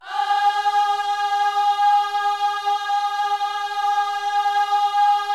OHS G#4B  -R.wav